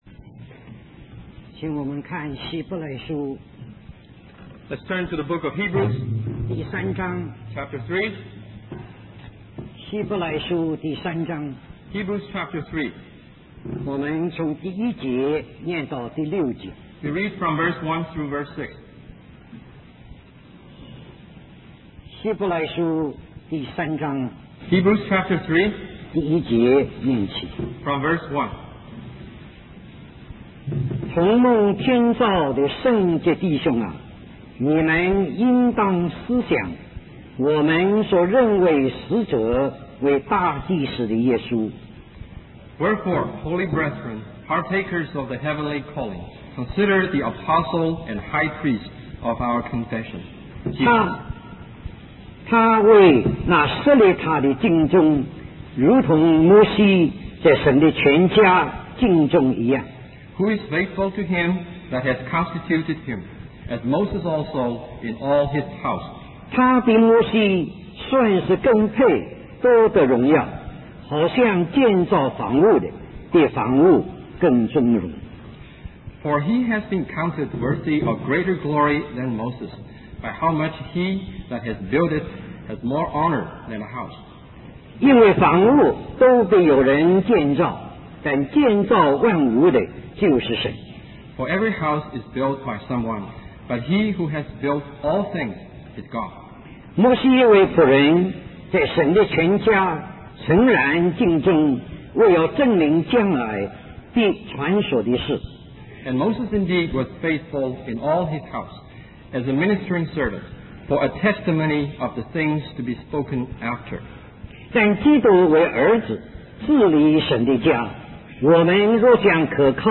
In this sermon, the speaker emphasizes the importance of accurate representation of God. He uses the example of the parable of the vineyard and the wicked gardeners to illustrate how Moses misrepresented God to the Israelites. The speaker highlights that Jesus is the perfect representation of God and quotes Jesus' words that we should not worry about material things but instead focus on looking outward and heavenward.